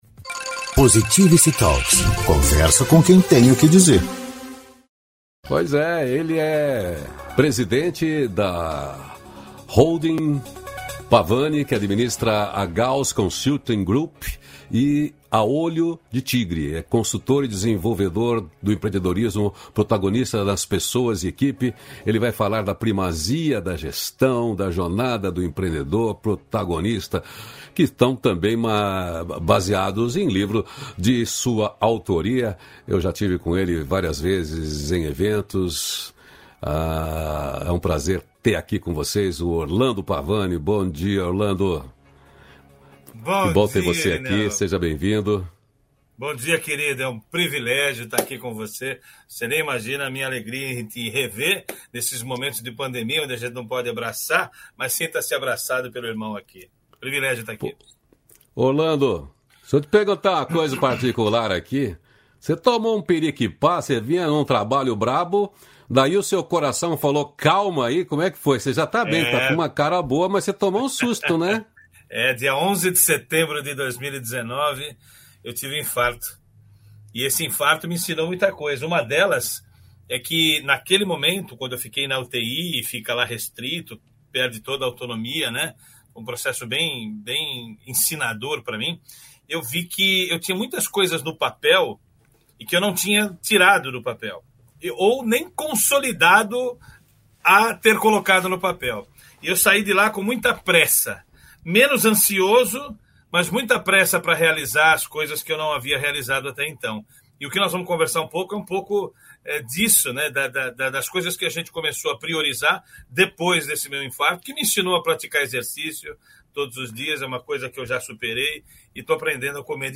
299-feliz-dia-novo-entrevista.mp3